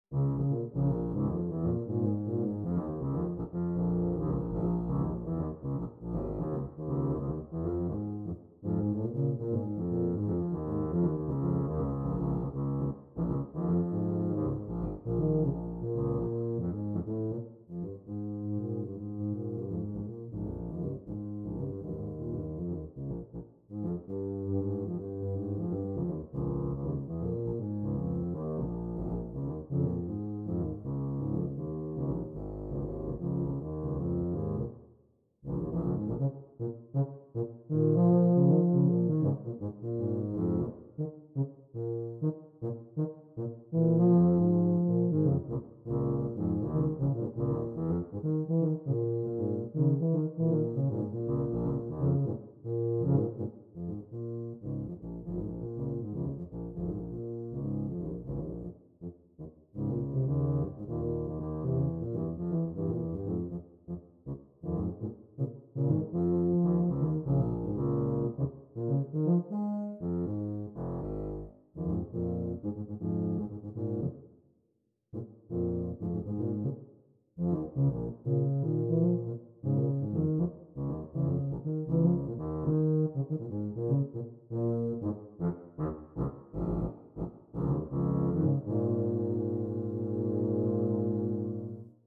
Gattung: Für 2 Tuben
Besetzung: Instrumentalnoten für Tuba